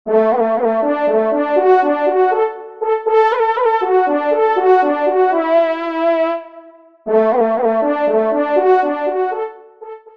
FANFARE
Localisation : Poitou-Charentes (Charente)
Extrait de l’audio « Ton de Vènerie »